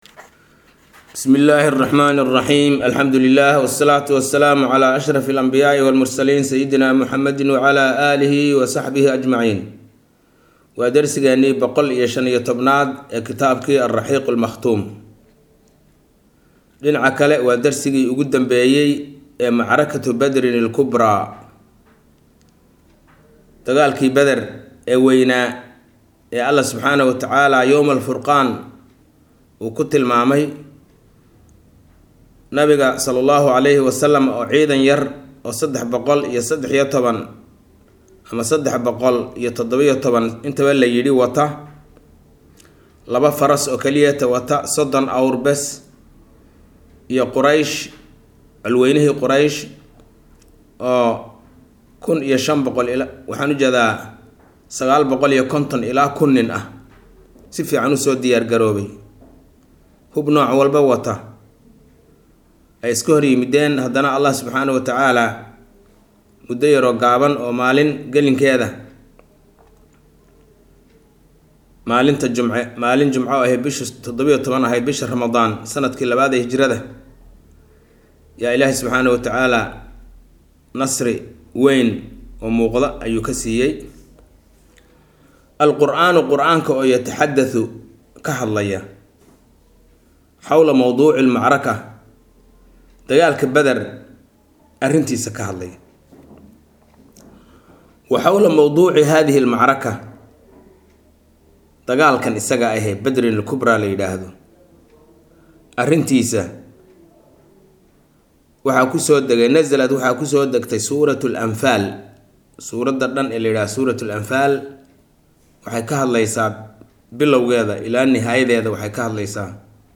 Maqal– Raxiiqul Makhtuum – Casharka 115aad